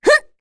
Rehartna-Vox_Jump2_kr.wav